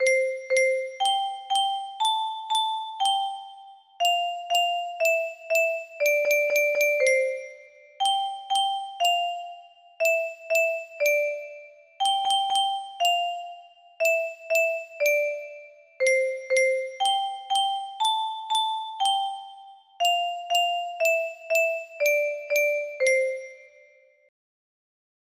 ABC test music box melody